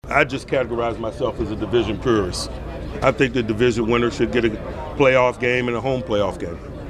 Tomlin held a 25-minute session with the media, fielding questions about his team and his role at the meetings, where he is a member of the competition committee.